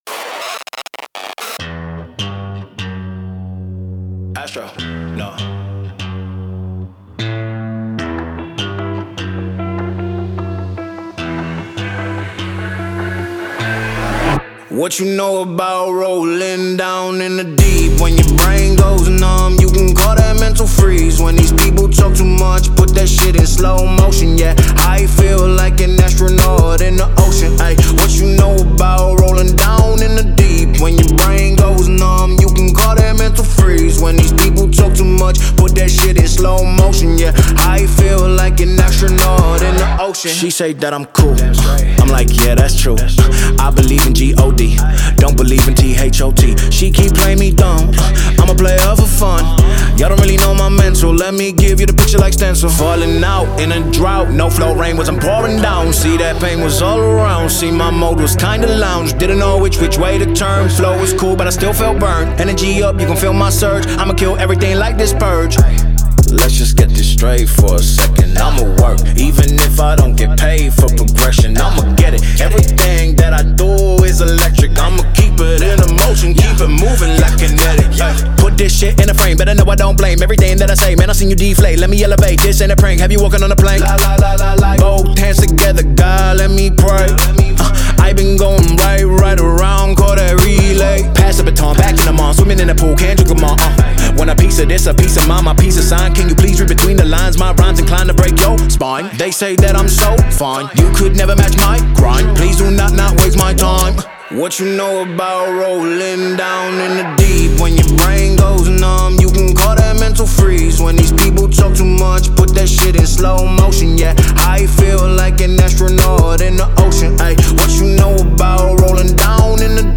ژانرهای :  هیپ هاپ / رپ